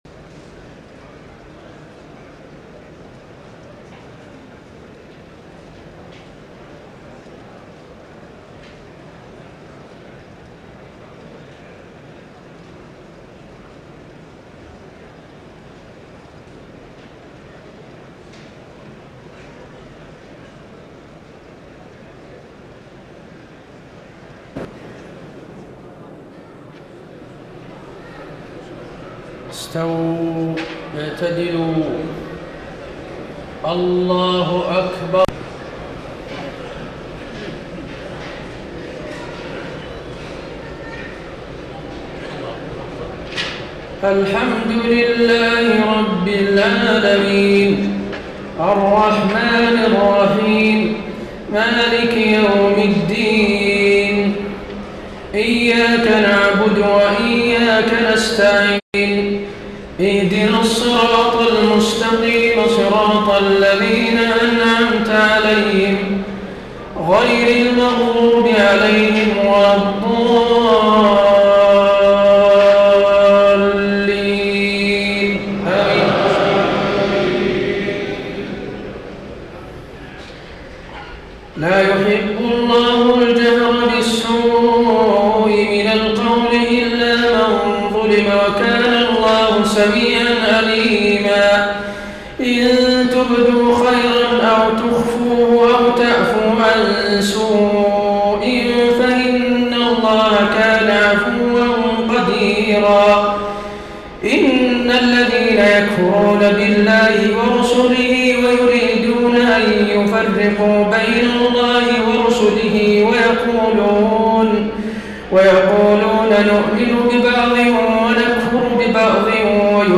تهجد ليلة 26 رمضان 1433هـ من سورتي النساء (148-176) و المائدة (1-40) Tahajjud 26 st night Ramadan 1433H from Surah An-Nisaa and AlMa'idah > تراويح الحرم النبوي عام 1433 🕌 > التراويح - تلاوات الحرمين